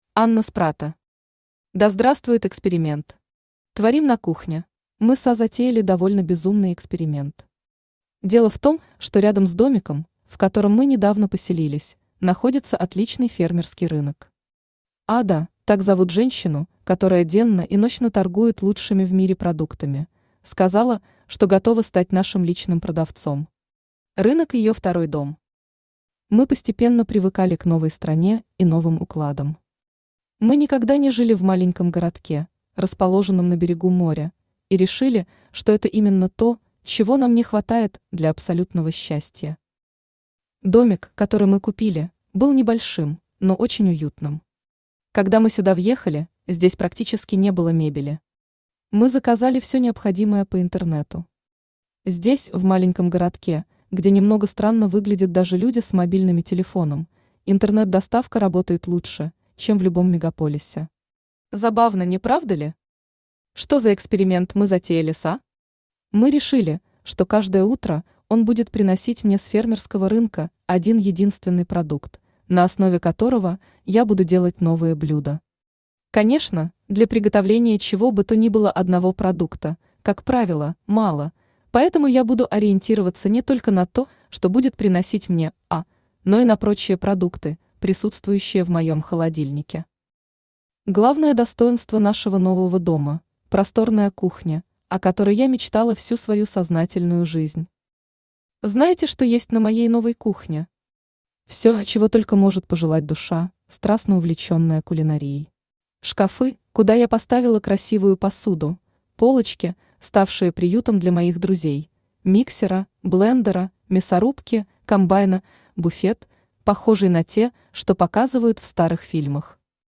Аудиокнига Да здравствует эксперимент! Творим на кухне | Библиотека аудиокниг